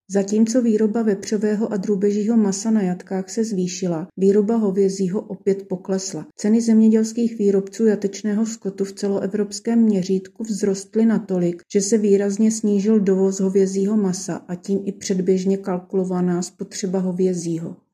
Vyjádření